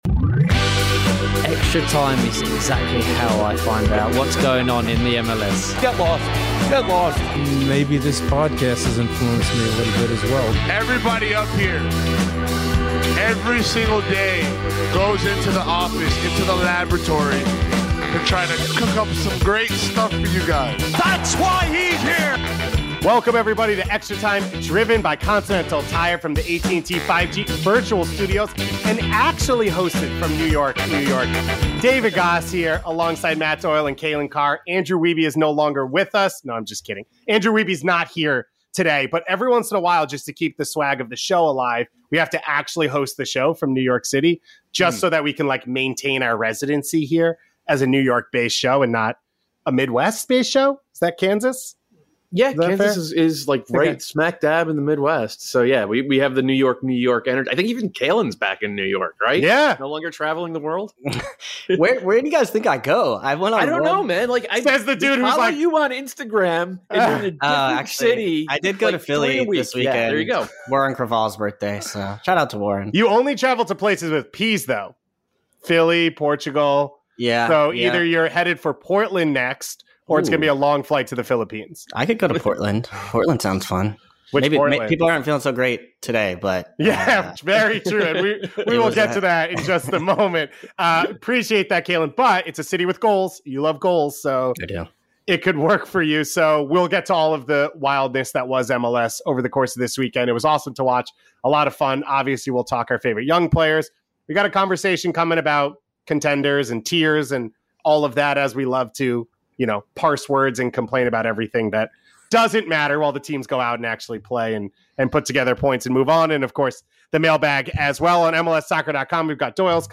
Interview: RSL forward Bobby Wood